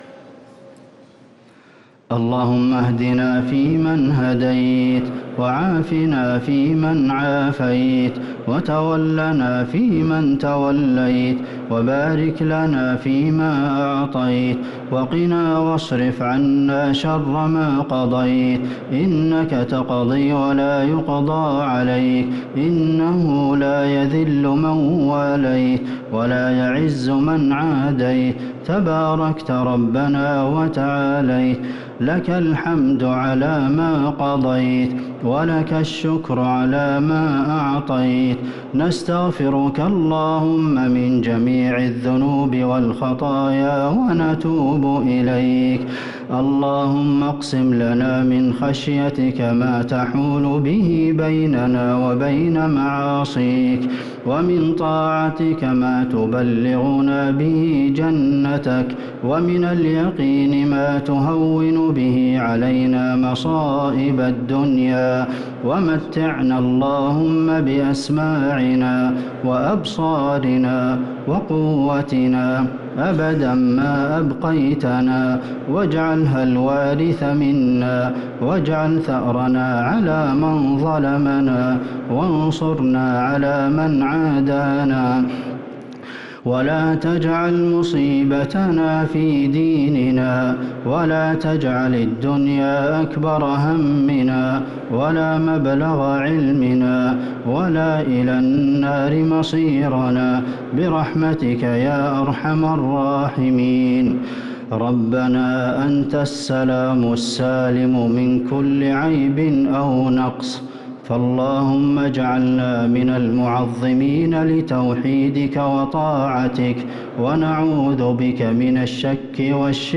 دعاء القنوت ليلة 15 رمضان 1444هـ | Dua for the night of 15 Ramadan 1444H > تراويح الحرم النبوي عام 1444 🕌 > التراويح - تلاوات الحرمين